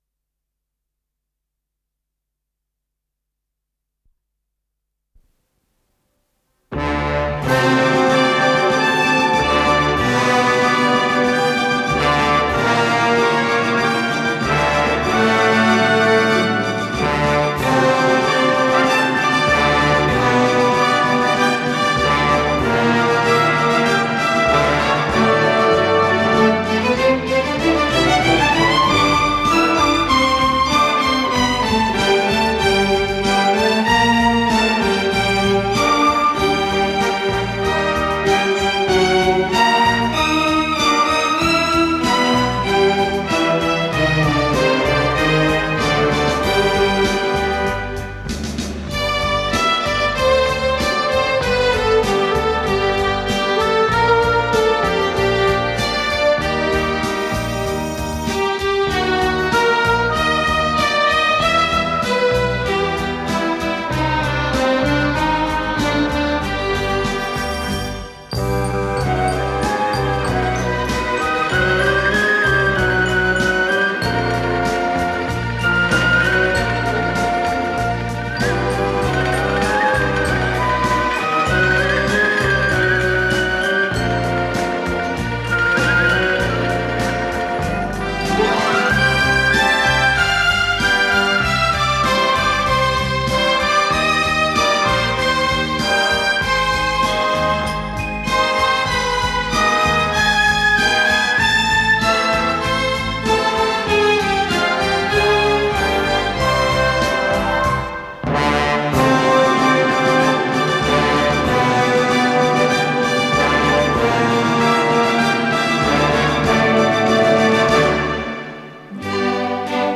с профессиональной магнитной ленты
до мажор
Скорость ленты38 см/с